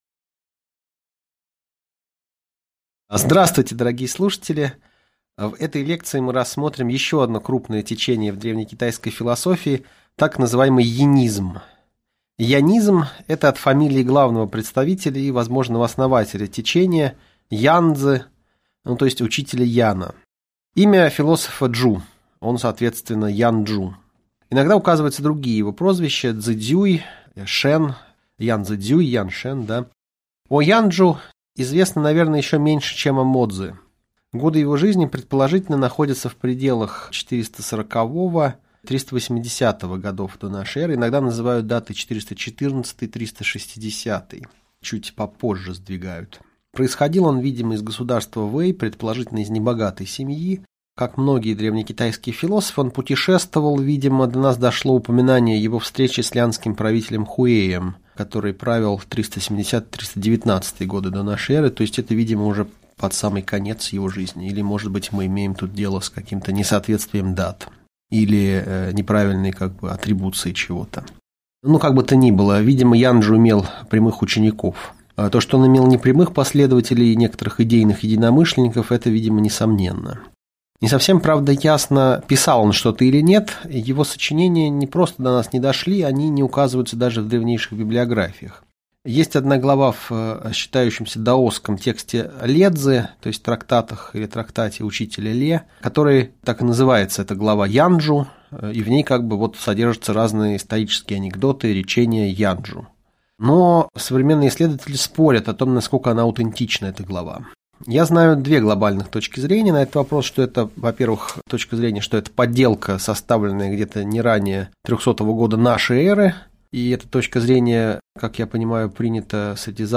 Аудиокнига Лекция «Ян Чжу и янизм» | Библиотека аудиокниг